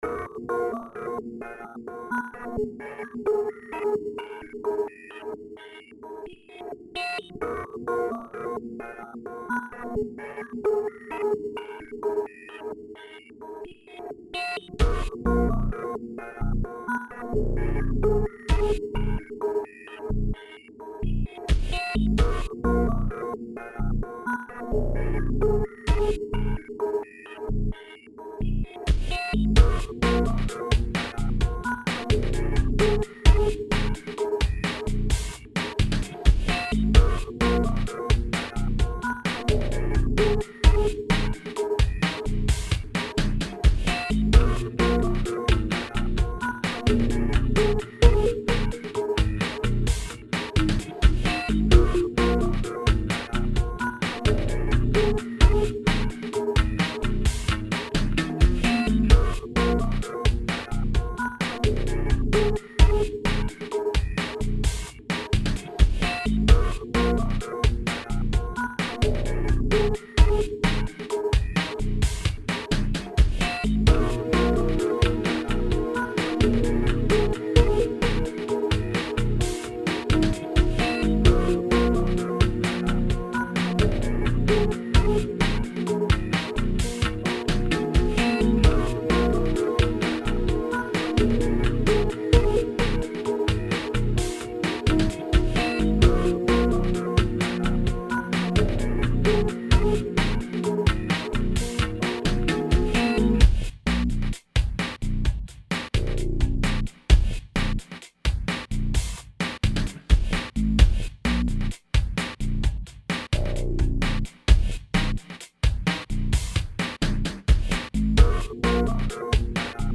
Lately I've been playing with some music software that allows you to make loop based music.
It has an electronica feel to it.